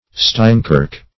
steinkirk - definition of steinkirk - synonyms, pronunciation, spelling from Free Dictionary
Steinkirk \Stein"kirk`\, n.